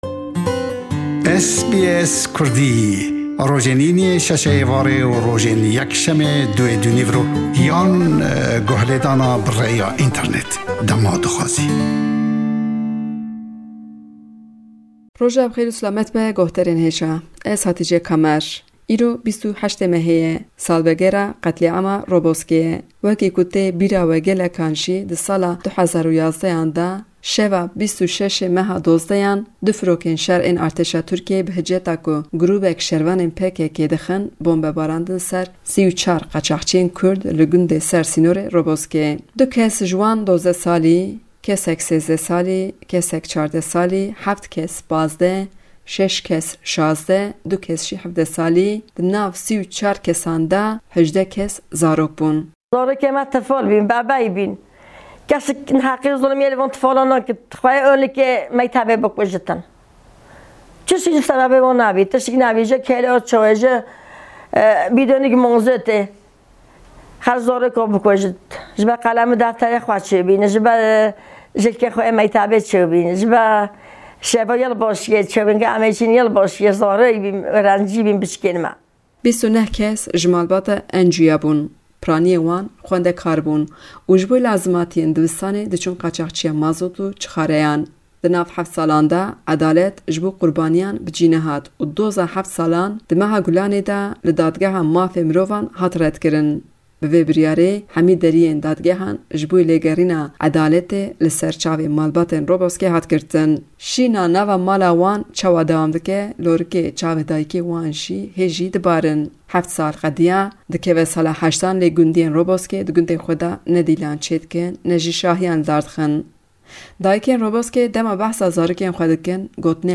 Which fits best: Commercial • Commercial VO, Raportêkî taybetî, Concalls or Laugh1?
Raportêkî taybetî